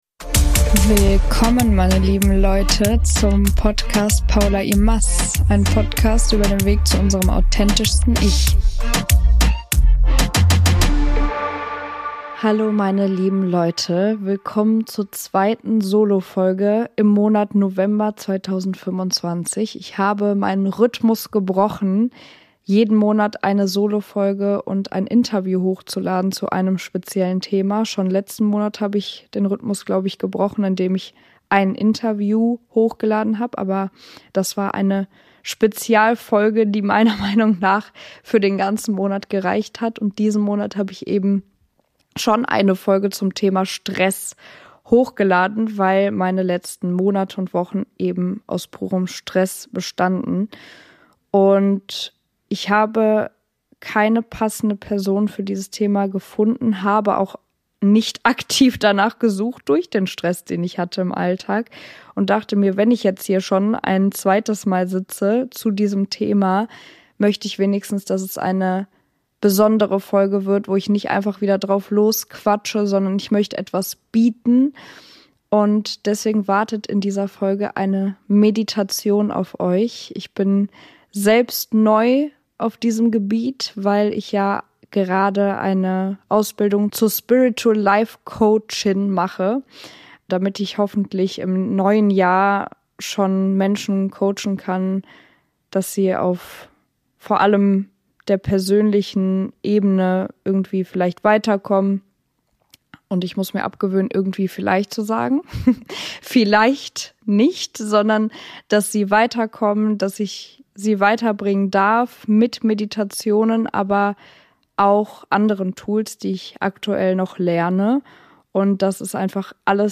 Beschreibung vor 4 Monaten In dieser Folge habe ich ein kleines Geschenk für euch: eine geführte Meditation, die euch dabei unterstützt, Stress sanft loszulassen und innere Klarheit zu finden. Nehmt euch ein paar Minuten Zeit für euch selbst, atmet durch und lasst euch führen - eine schöne Möglichkeit, nach der letzten Folge über Stress in Ruhe anzukommen.